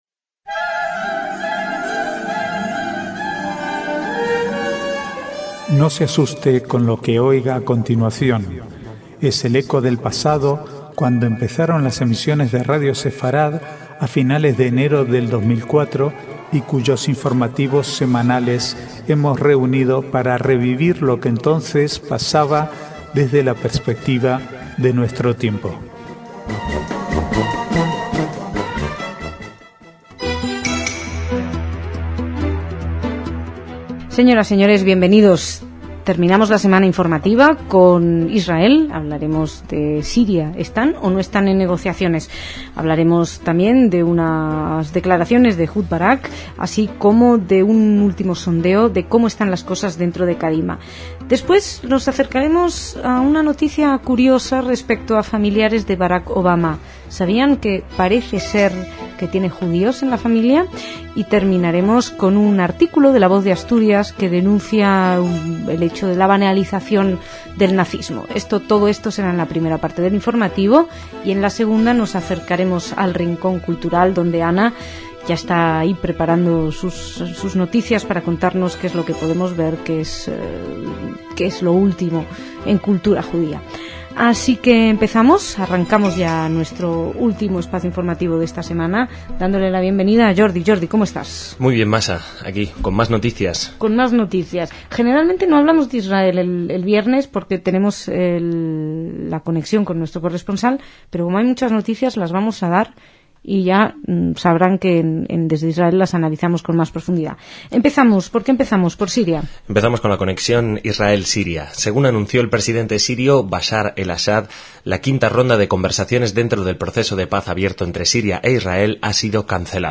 Archivo de noticias del 5 al 10/9/2008